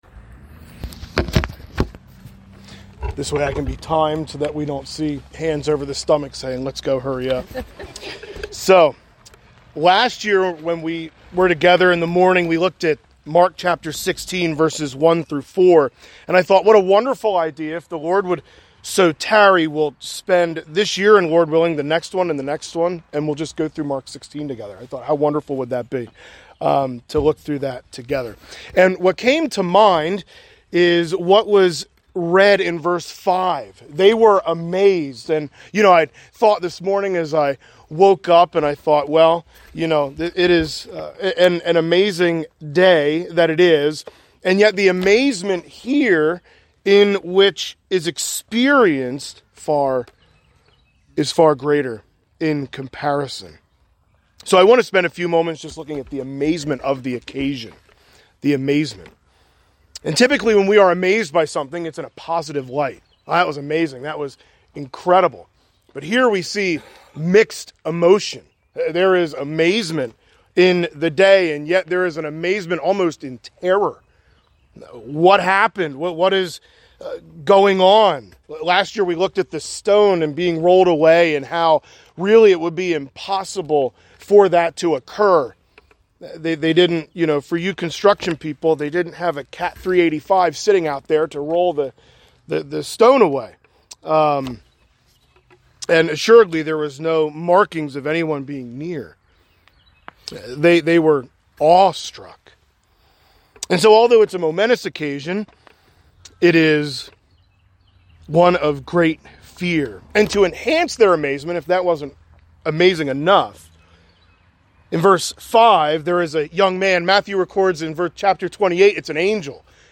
Sunrise Service